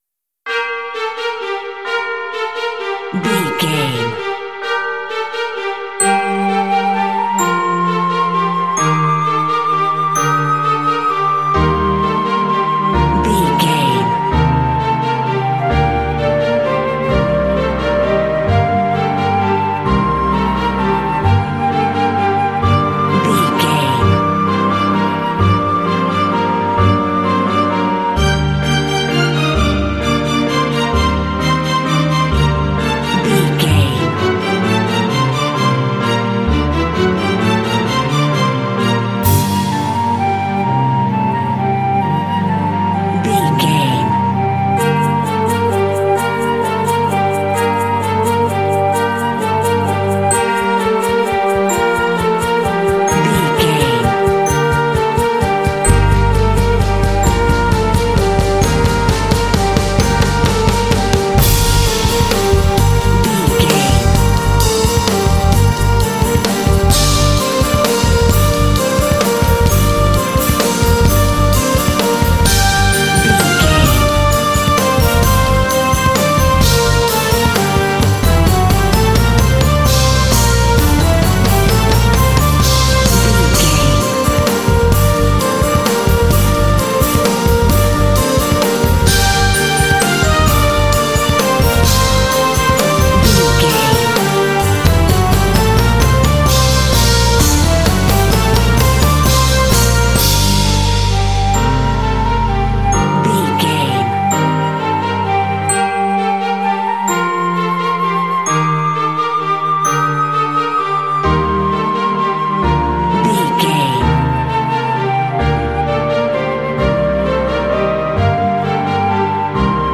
Aeolian/Minor
Slow
romantic
epic
proud
drums
strings
flute
orchestra
christmas